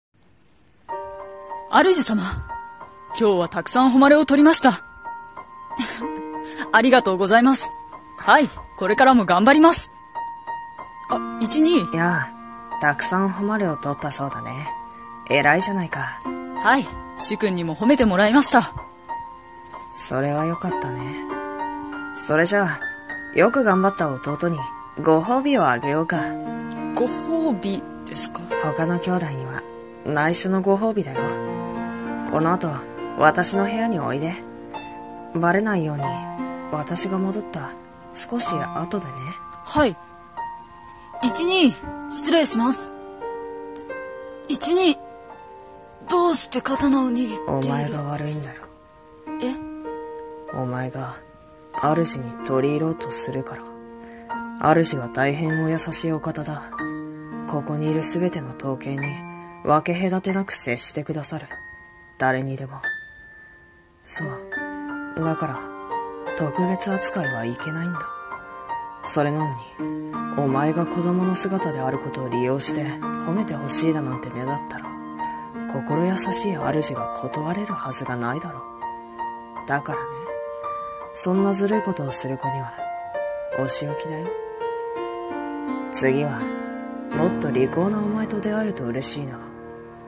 【刀剣乱舞】アザミ【声劇台本】